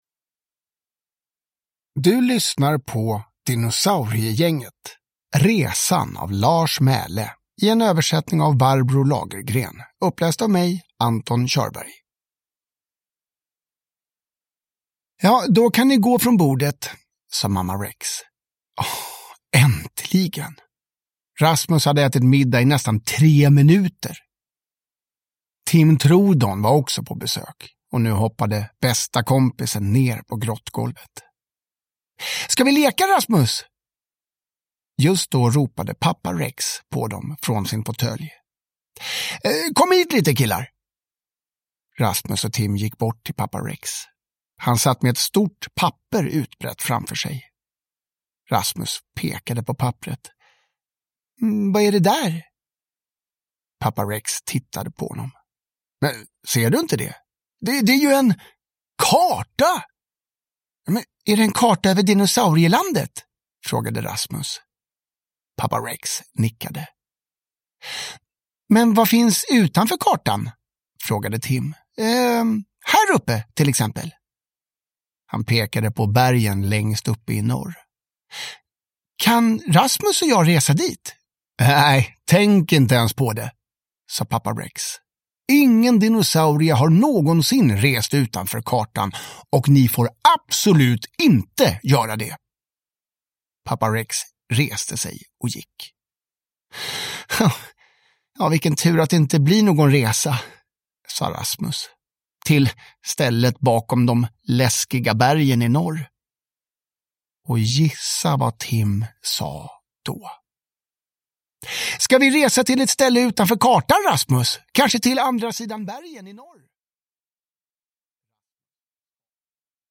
Resan (ljudbok) av Lars Mæhle